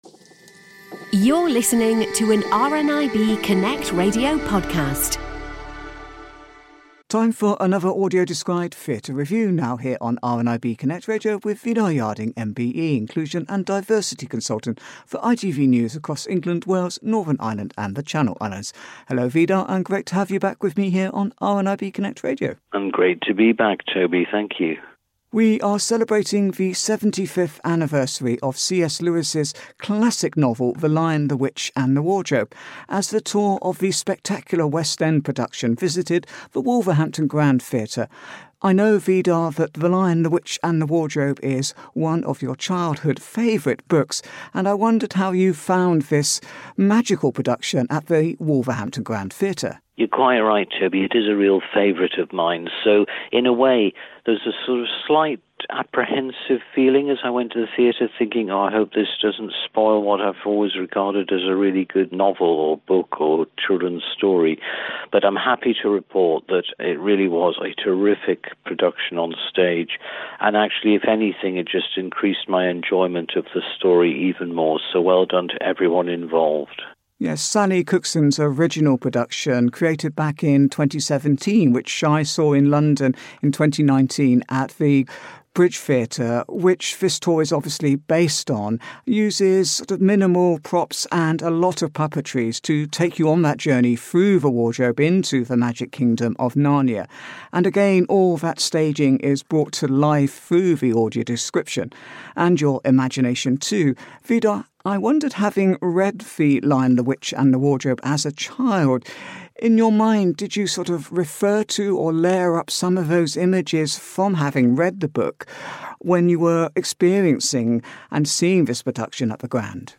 AD Theatre Review